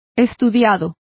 Complete with pronunciation of the translation of studied.